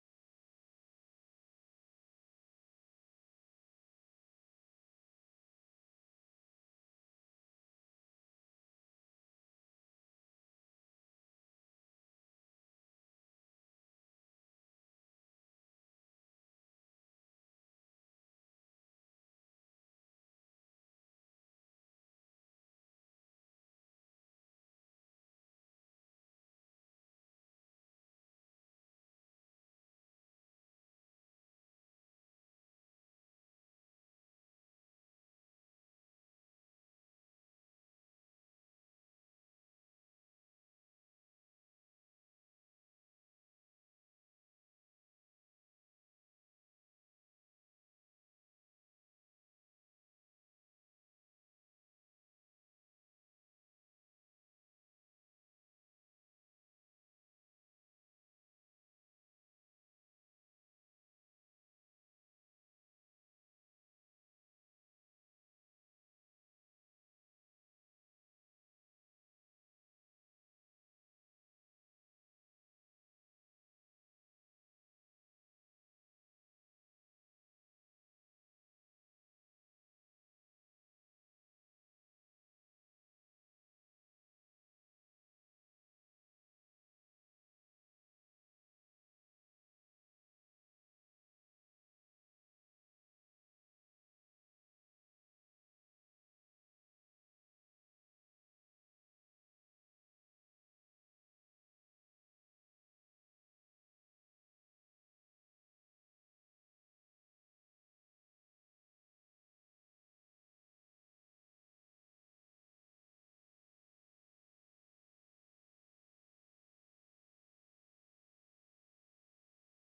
GCC-OJ-February-5-Sermon.mp3